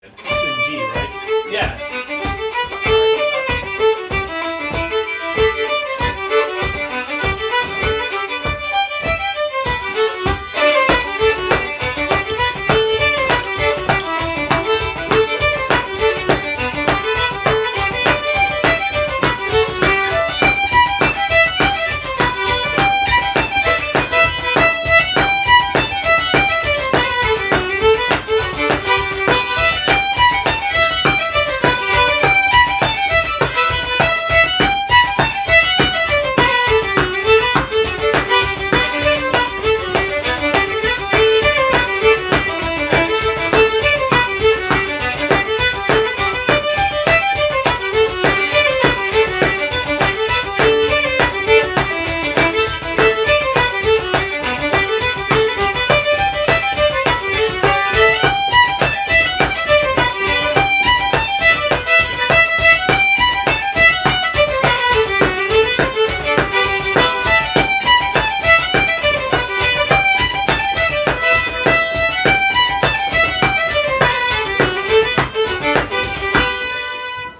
Sessions are open to all instruments and levels, but generally focus on the melody.
Composer Traditional Type Reel Key G Recordings